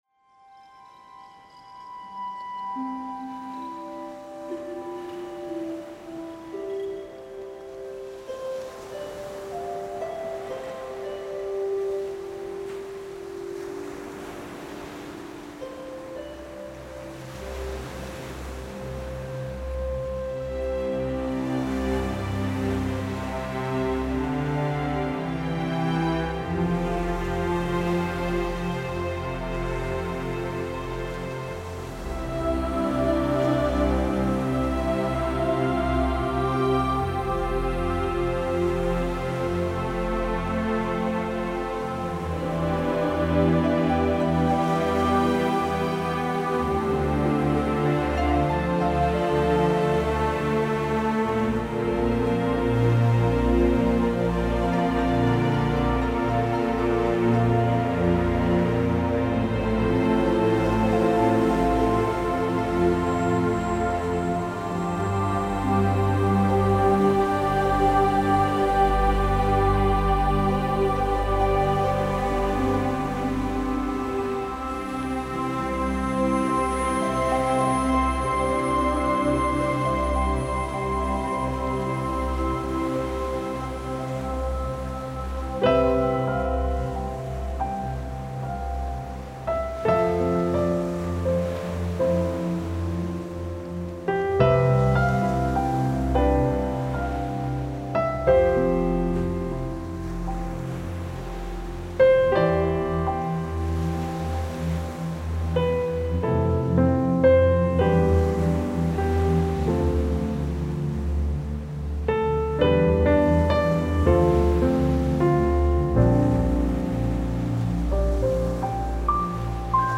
آهنگ زیبای کنار دریا برای مدیتیشن و آرامش روح و روان
15_-_seaside.mp3